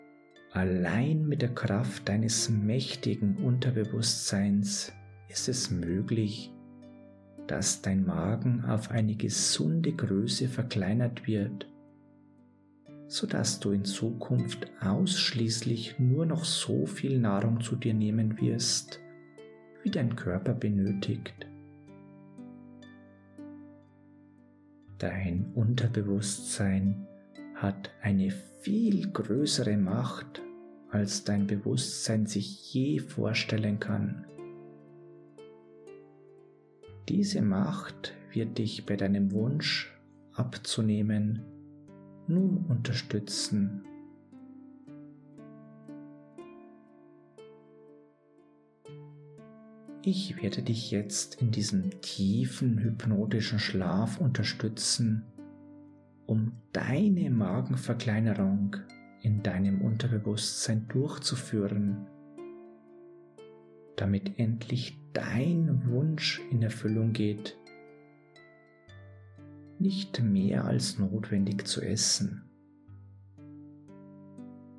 Unsere Hypnose-Sitzungen sind sanft, sicher und ohne Nebenwirkungen.
G2023-Hypnotische-Magenverkleinerung-Hauptsitzung-Hoerprobe.mp3